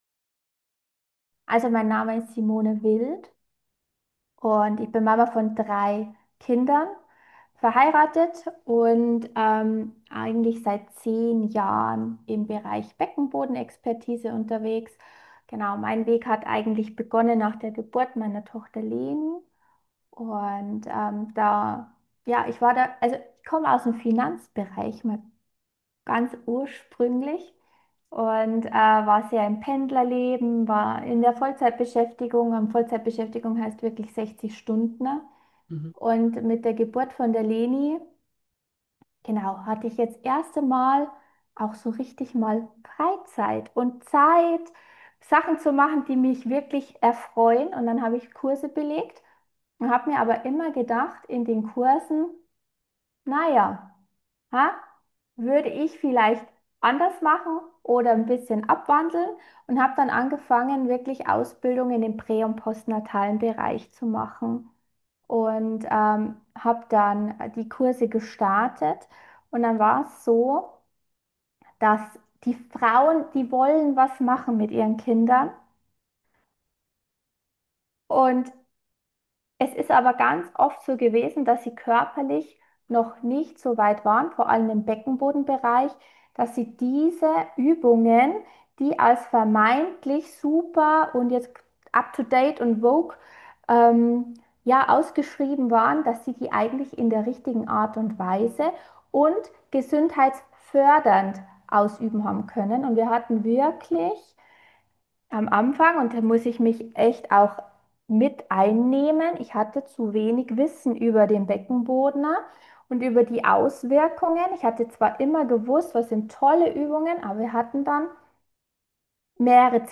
Interview-Beckenboden-Audio.mp3